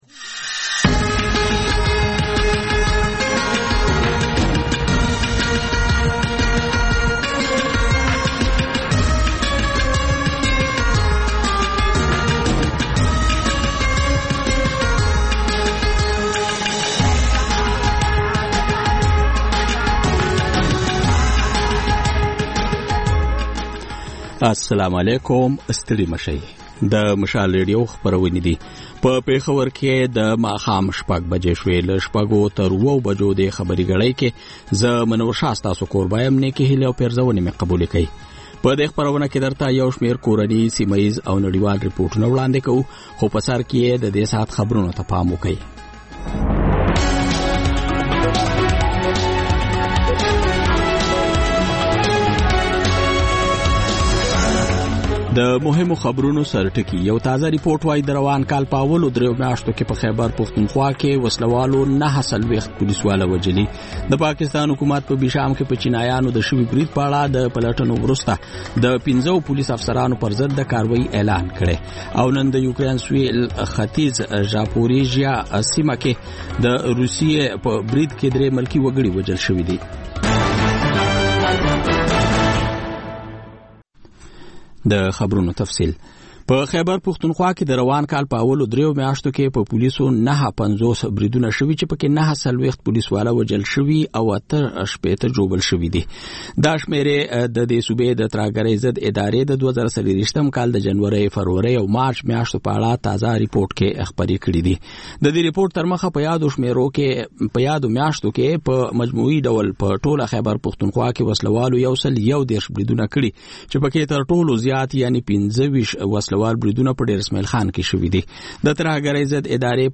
د مشال راډیو د ۱۴ ساعته خپرونو دویمه او وروستۍ خبري ګړۍ. په دې خپرونه کې تر خبرونو وروسته بېلا بېل سیمه ییز او نړیوال رپورټونه، شننې، مرکې، رسنیو ته کتنې، کلتوري او ټولنیز رپورټونه خپرېږي.